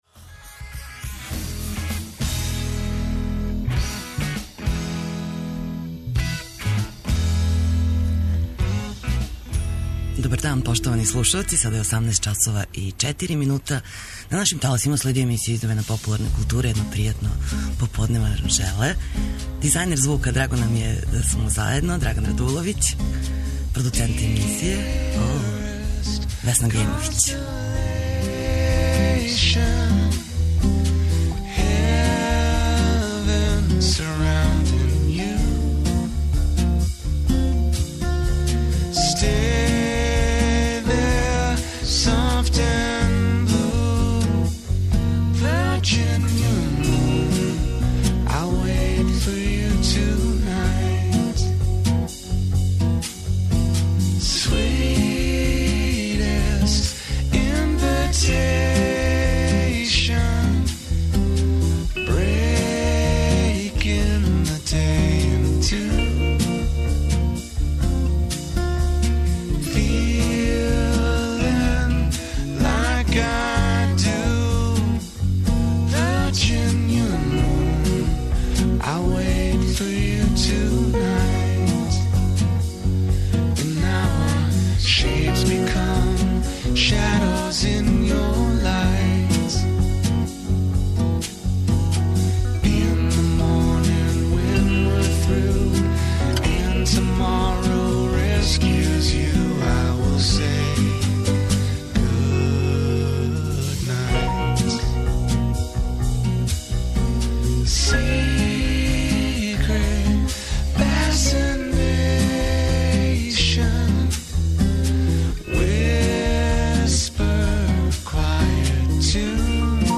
У емисији ћемо разговарати са Браниславом Бабићем Кебром из Обојеног програма, о текућим догађајима, везаним за бенд.